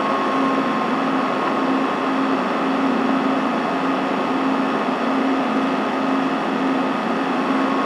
Sfx_tool_spypenguin_idle_interior_01.ogg